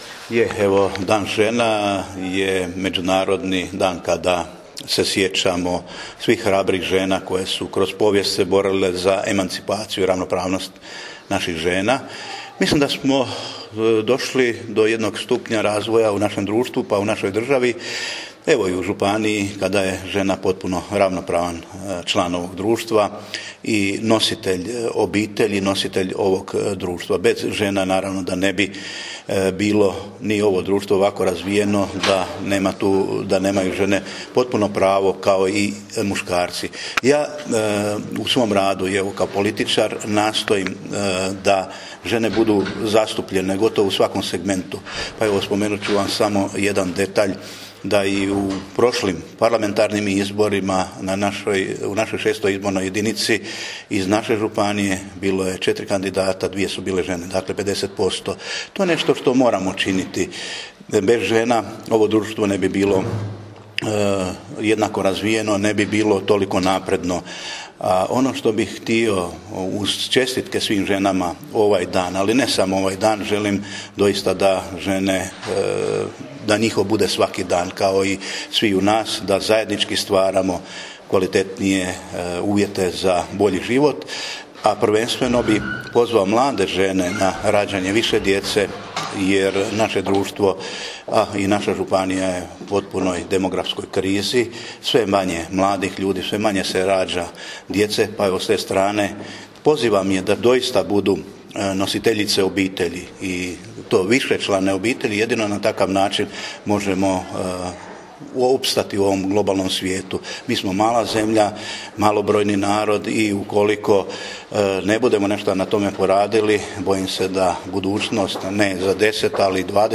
Ovdje poslušajte izjavu koju je u ovoj prigodi župan Ivo Žinić dao novinarima: